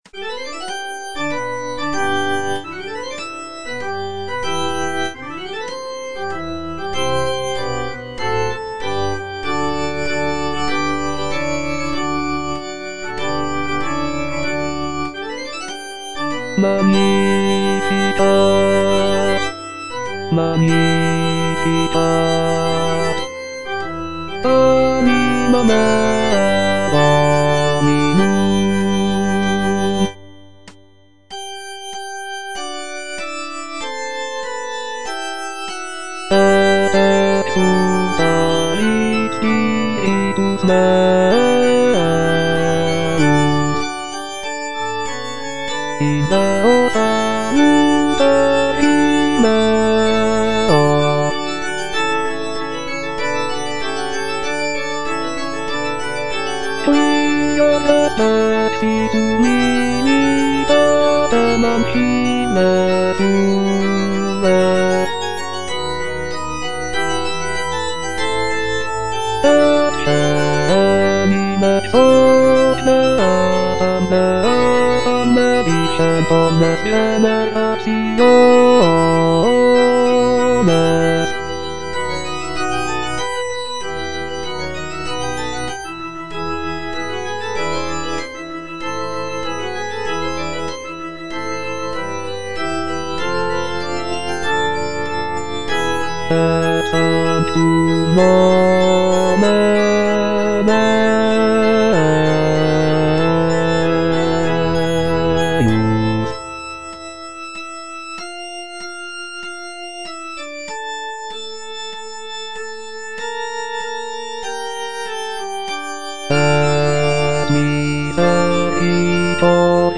B. GALUPPI - MAGNIFICAT Magnificat anima mea - Bass (Voice with metronome) Ads stop: auto-stop